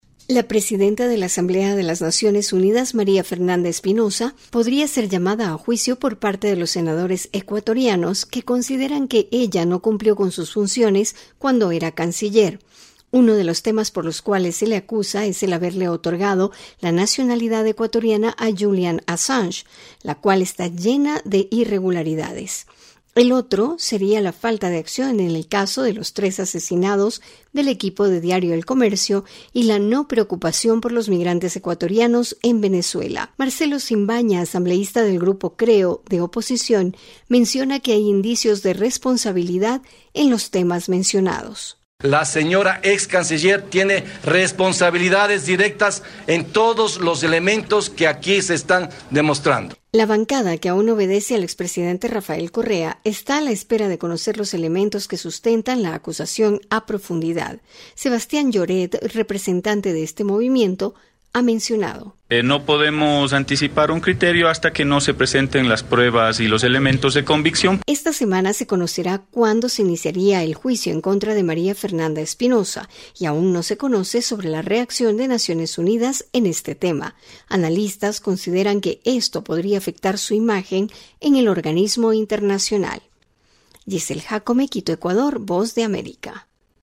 VOA: Informe desde Ecuador